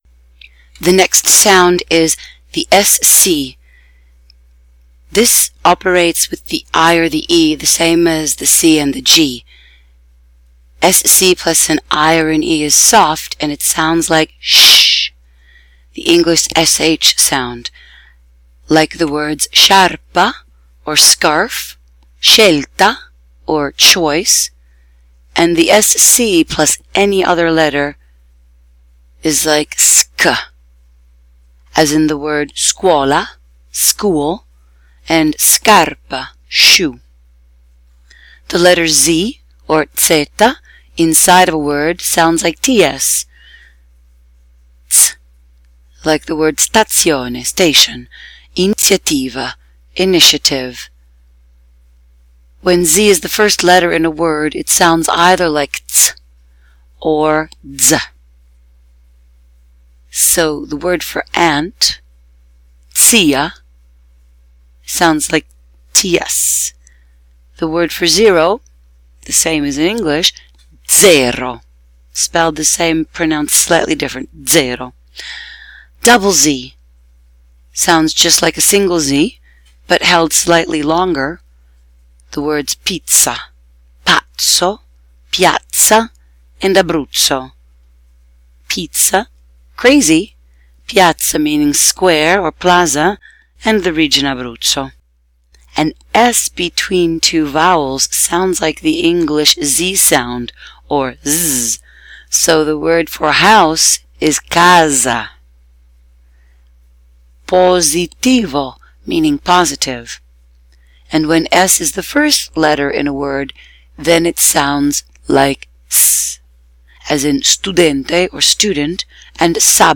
Italian Alphabet & Special Sounds
Phonetic Pronunciation Guide (letters between slash marks / / indicate the English phonetic pronunciation)